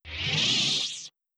Melee Sword Sounds
Deactivate Lightsaber Sound.wav